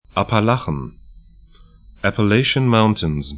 Pronunciation
Appalachen apa'laxn Appalachian Mountains